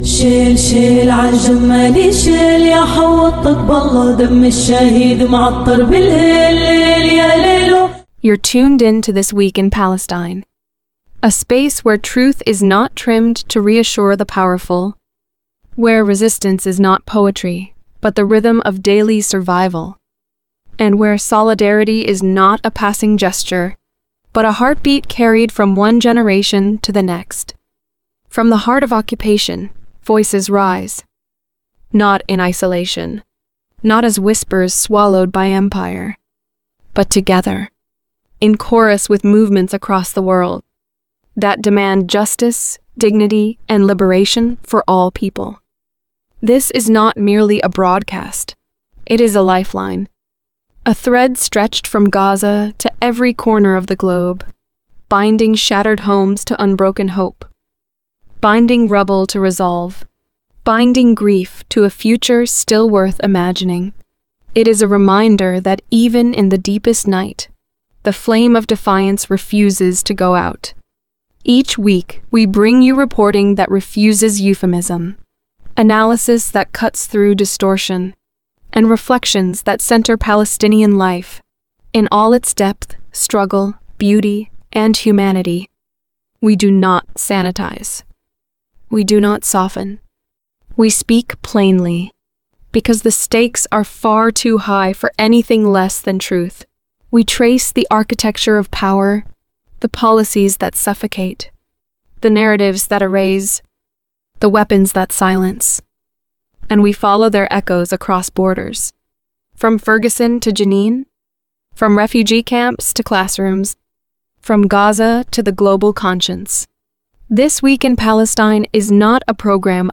Weekly Program